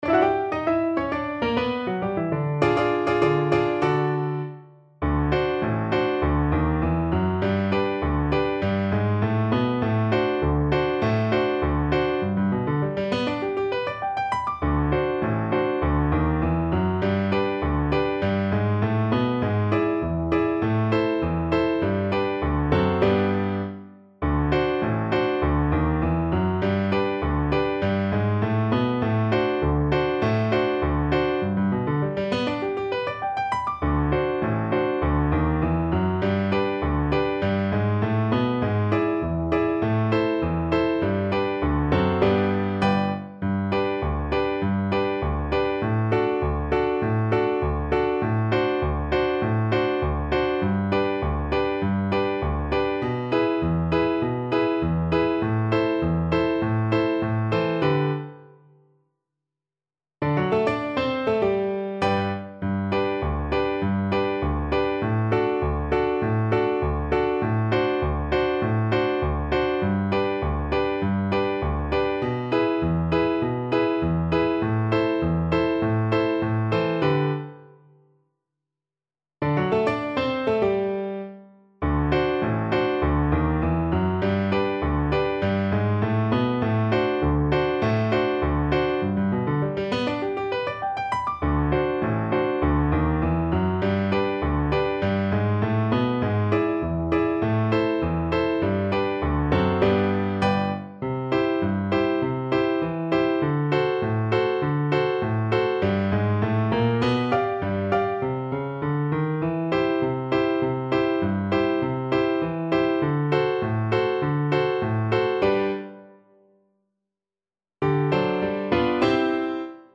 Moderato